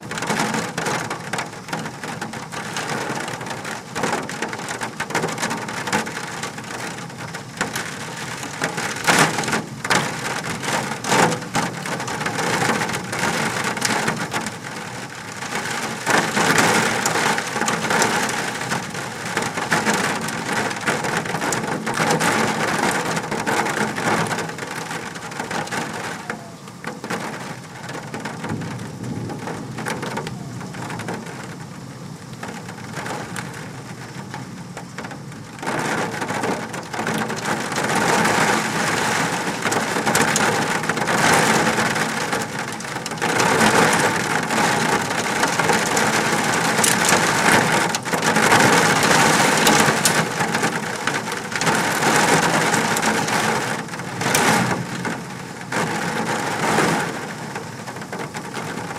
Звуки града
Крупный град барабанит по крыше дома